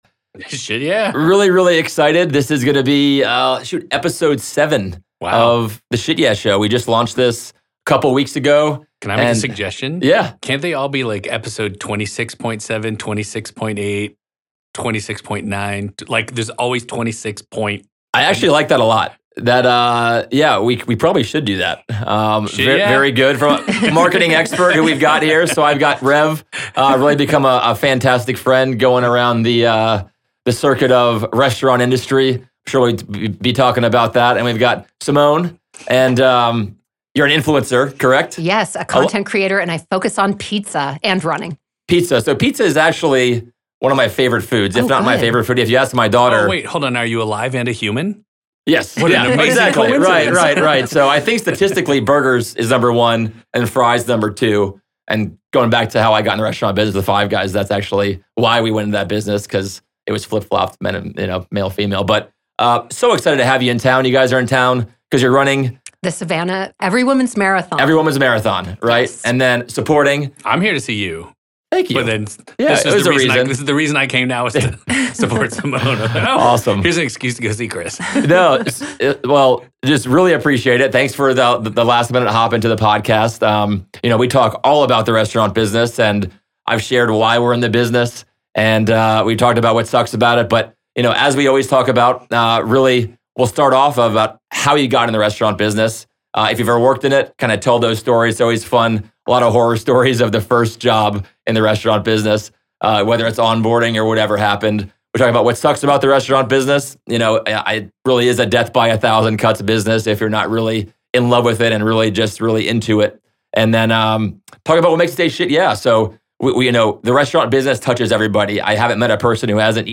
Expect engaging stories, laughs, and plenty of "SHIT YEAH!" moments.